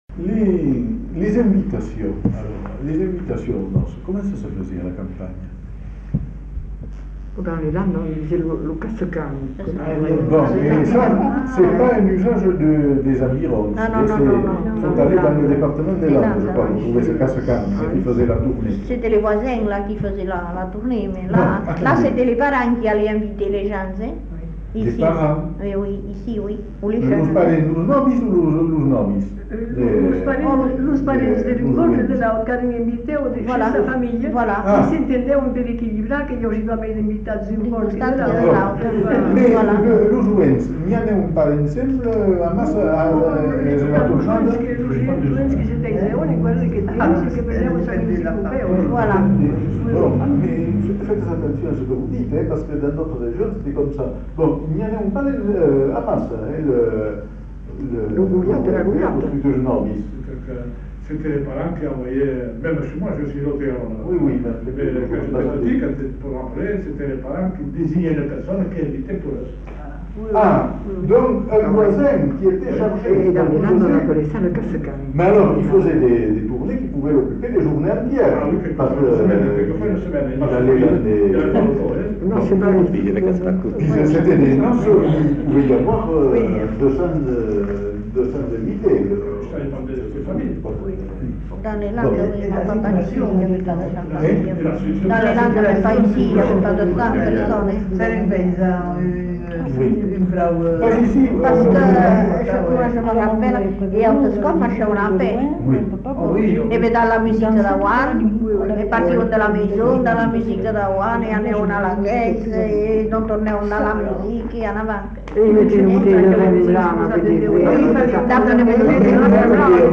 Lieu : Bazas
Genre : témoignage thématique
Instrument de musique : violon